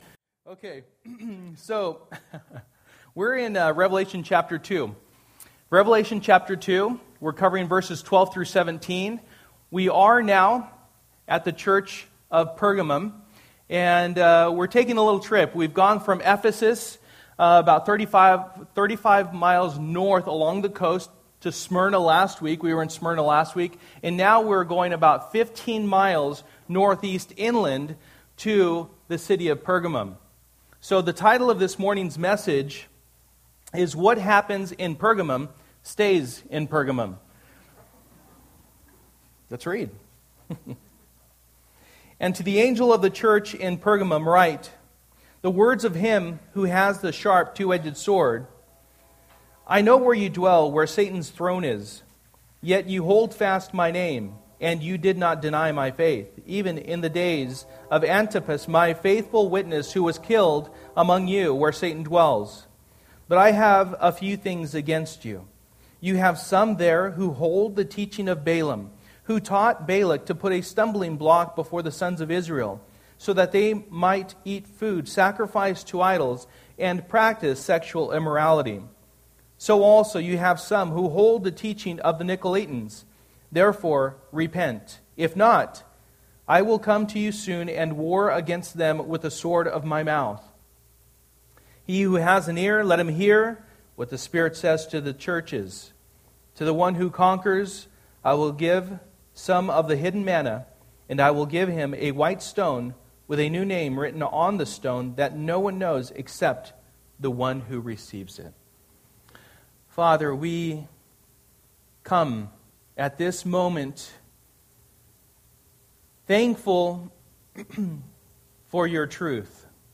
Passage: Revelation 2:12-17 Service: Sunday Morning